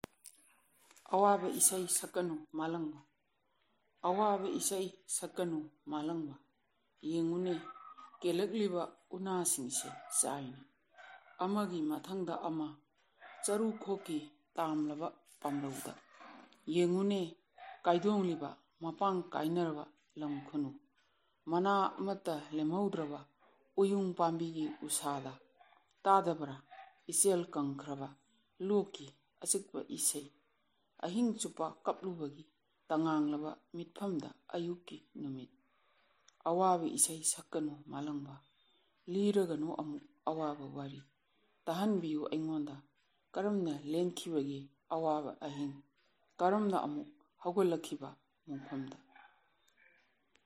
Listen to the poet read her poem in the original language: